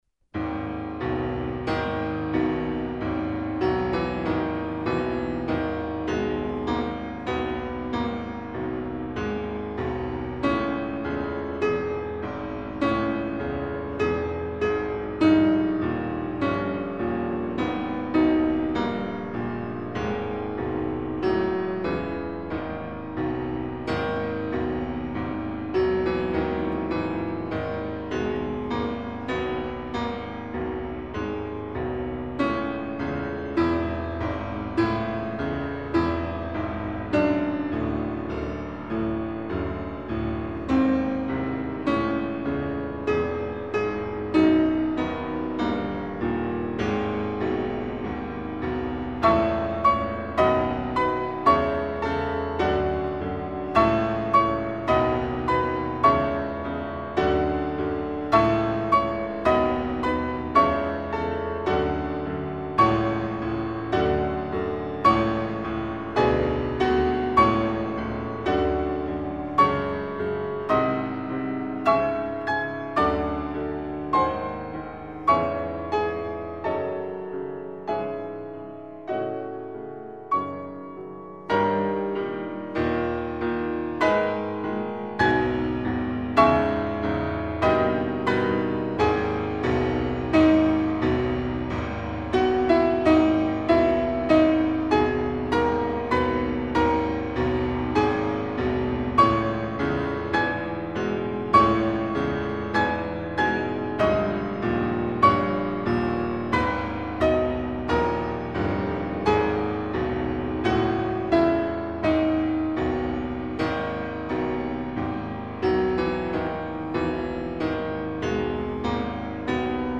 L'accompagnamento, sulle note gravi del pianoforte, ha dal principio alla fine una scansione ritmica implacabile.
Bydlo (versione pianistica)